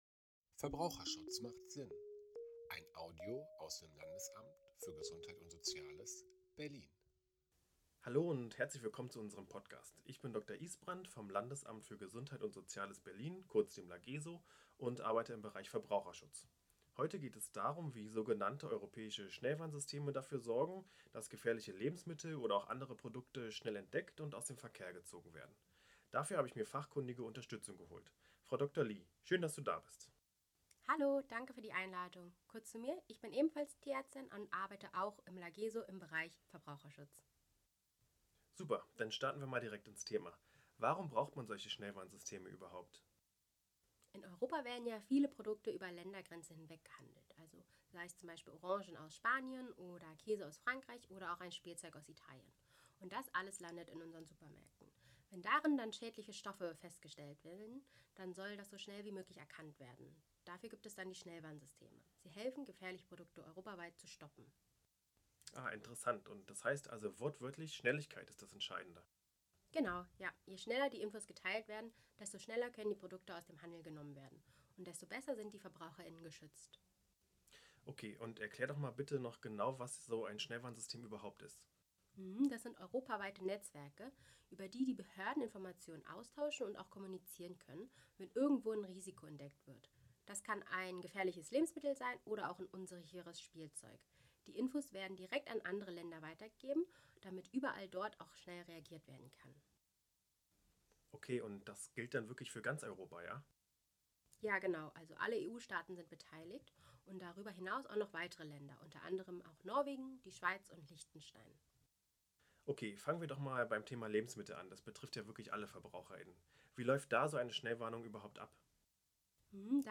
Unser Audio-Expertentalk zum Thema Schnellwarnungen und Lebensmittelsicherheit
Frühwarnsysteme für Lebensmittel erklären: In unserem Audio-Expertentalk erläutern zwei Fachleute des Lageso, wie europäische Schnellwarnungen funktionieren, riskante Produkte rasch gestoppt werden und wie so die Lebensmittelsicherheit für Verbraucherinnen und Verbraucher verbessert wird.